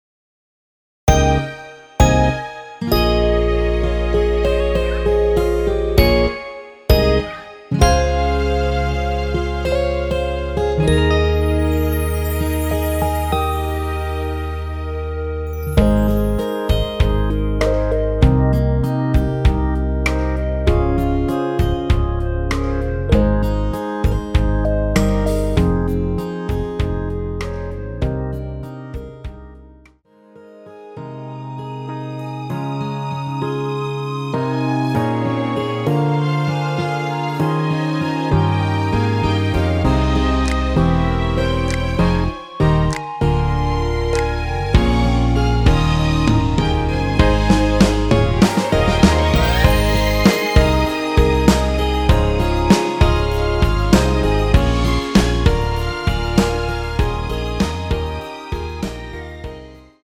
홈페이지에 등록된 여자키에서(+2)더 올린 멜로디 MR입니다.
원키에서(+6)올린 멜로디 포함된 MR입니다.
Ab
앞부분30초, 뒷부분30초씩 편집해서 올려 드리고 있습니다.